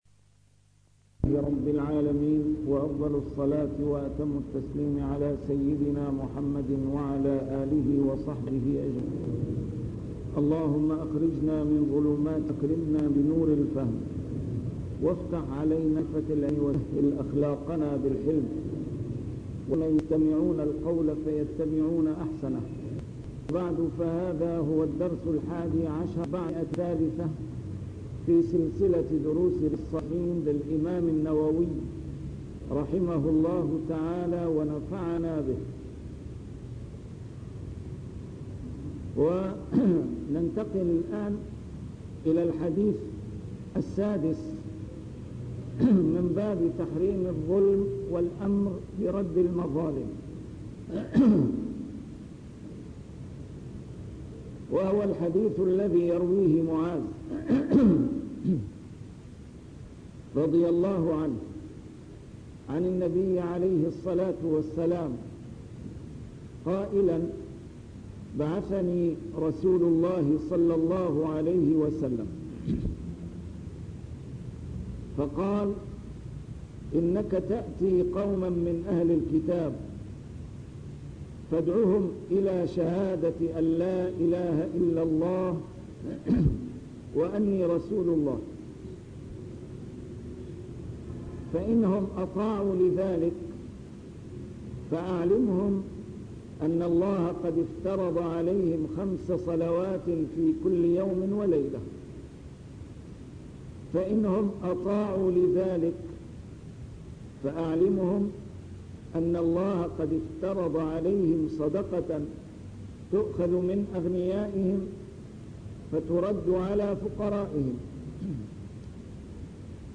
A MARTYR SCHOLAR: IMAM MUHAMMAD SAEED RAMADAN AL-BOUTI - الدروس العلمية - شرح كتاب رياض الصالحين - 311- شرح رياض الصالحين: تحريم الظلم